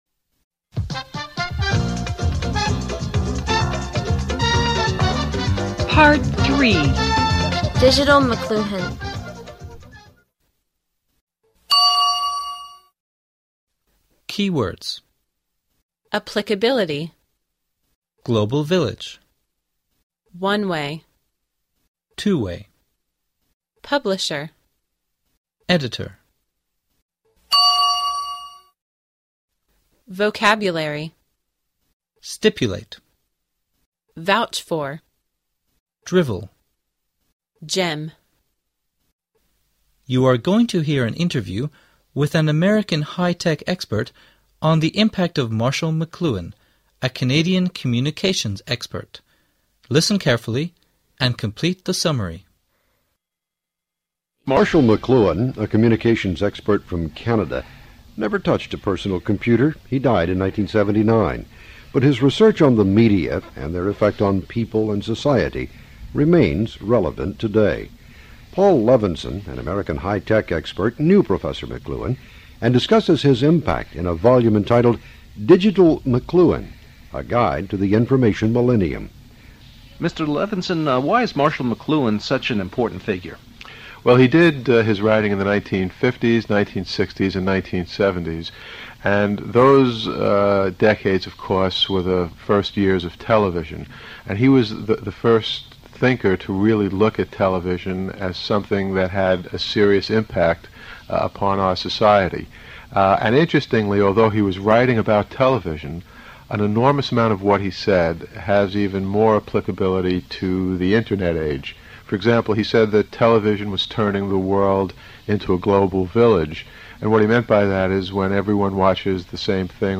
You're going to hear an interview with an American high-tech expert on the impact of Marshall McLuhan, a Canadian communications expert.Listen carefully and complete the summary.